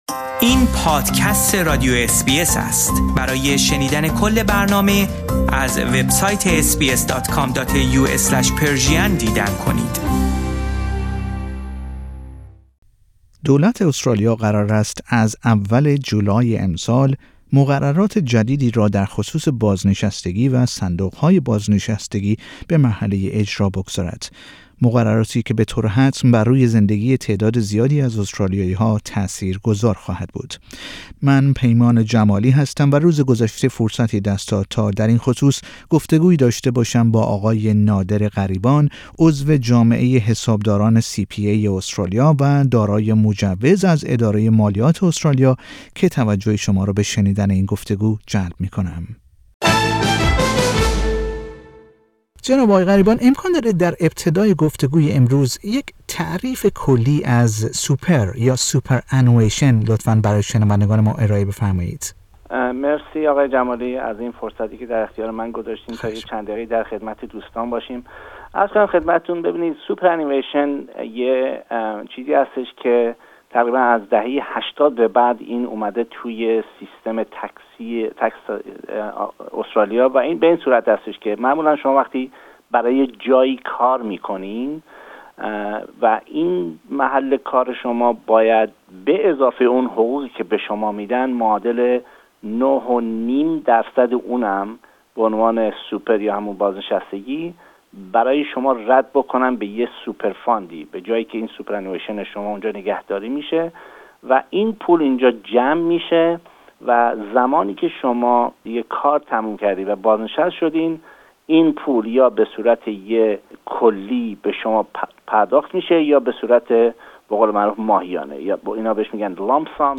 در گفتگو با برنامه فارسی رادیو اس بی ای